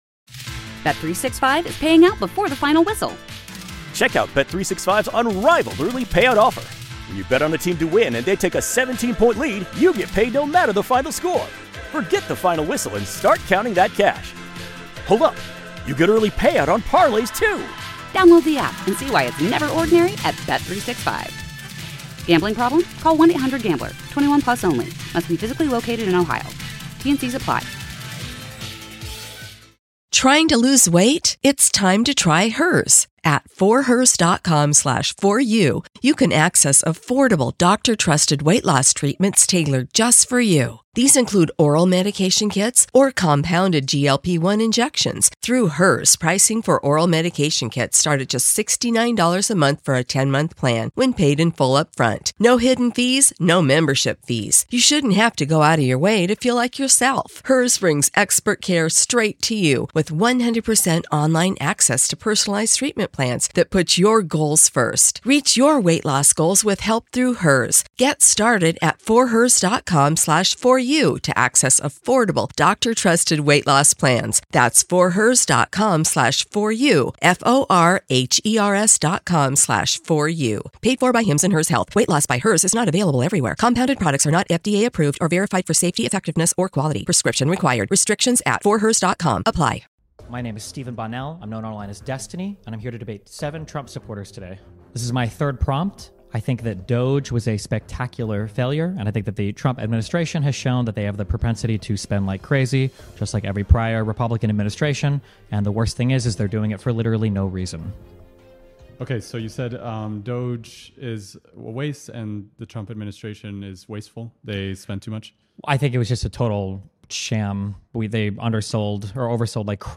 We believe debates should be moderated on an equal playing field and host live debates on controversial topics from science, religion, and politics several times a week.